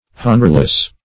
Honorless \Hon"or*less\, a. Destitute of honor; not honored.